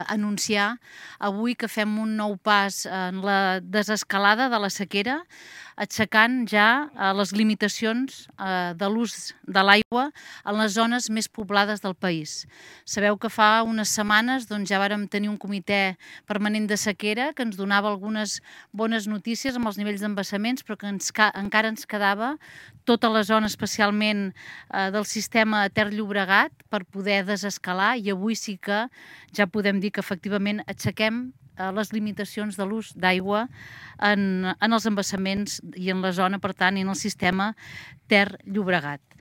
En una roda de premsa des del pantà de Sau, Paneque ha dit que el Ter-Llobregat se situarà en prealerta després que els embassaments hagin superat el 64% de la seva capacitat.